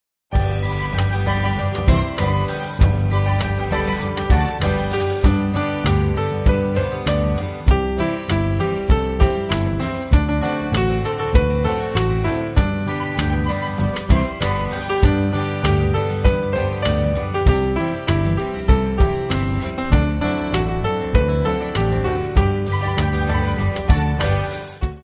Listen to a sample of the instrumental track